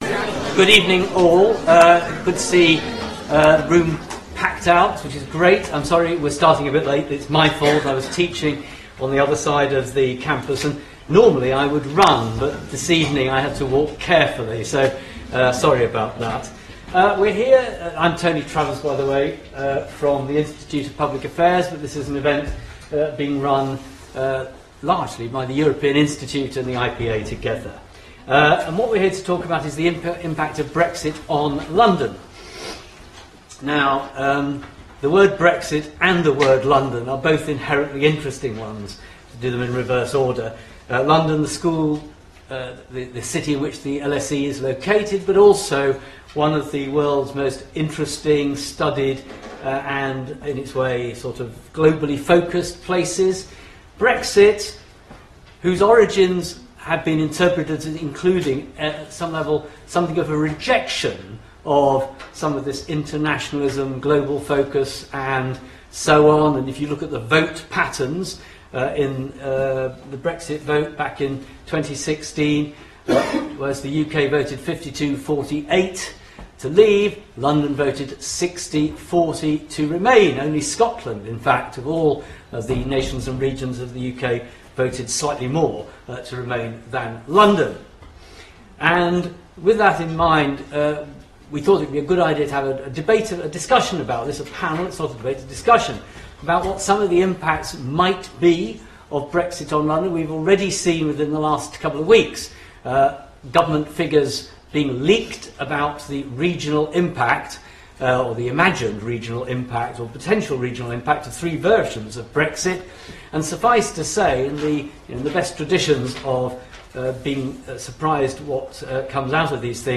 Events Upcoming public lectures and seminars
This panel will explore how London is different and how Brexit might affect the city’s future.